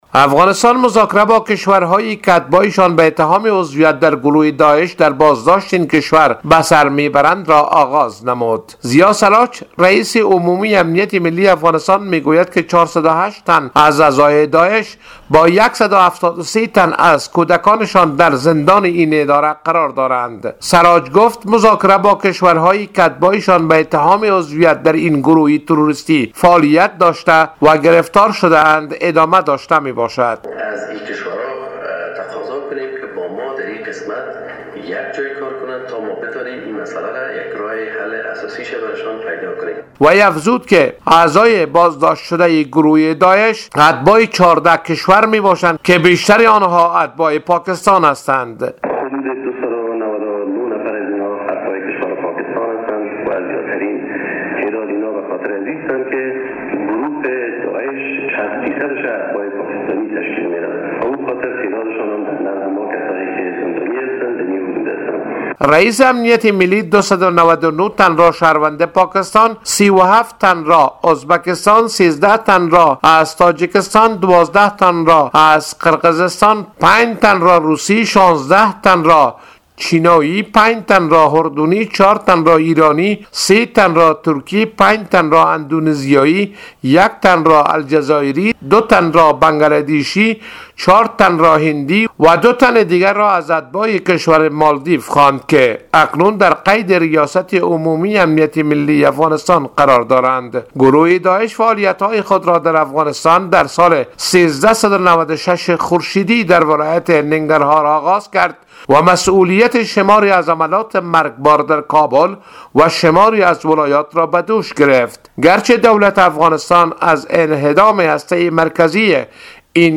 به گزارش خبرنگار رادیودری، ضیاءسراج رییس عمومی امنیت ملی افغانستان می گوید که ۴۰۸تن از اعضای داعش با ۱۷۳تن از کودکان شان در زندان این اداره قرار دارند.